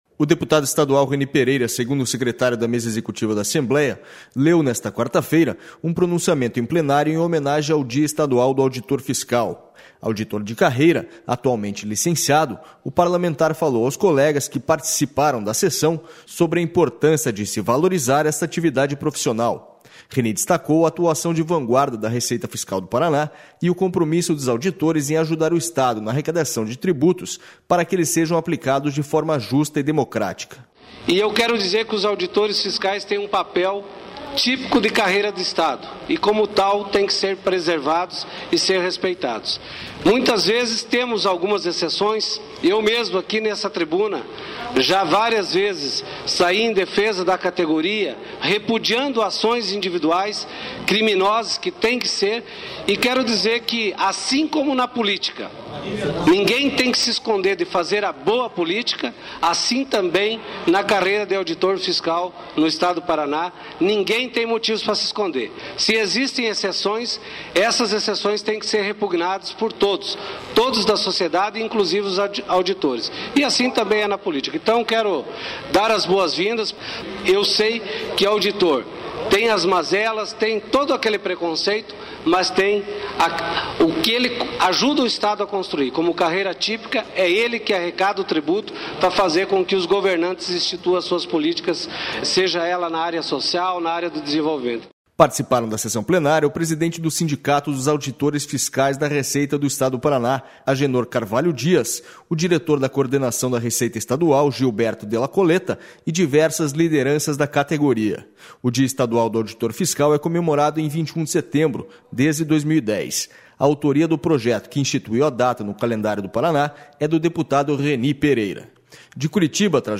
Auditores fiscais são homenageados durante sessão do Legislativo